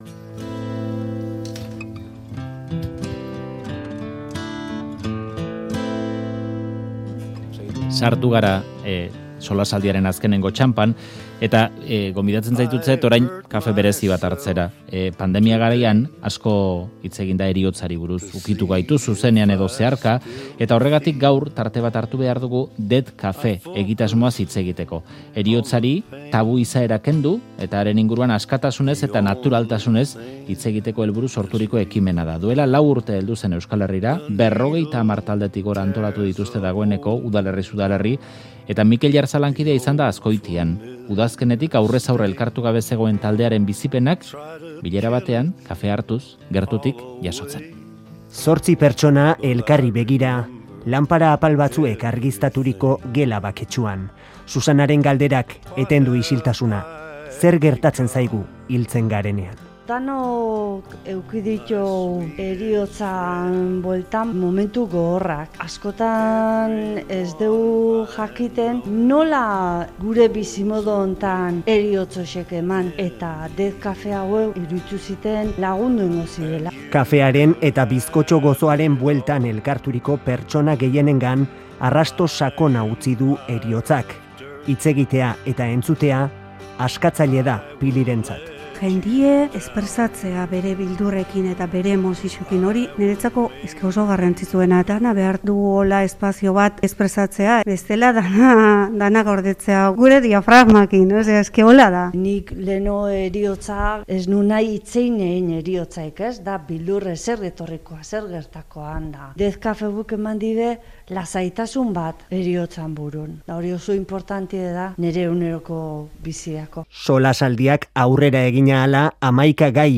Azkotiako Death Cafean entzundakoak bildu ditu, Faktoria albistegiak.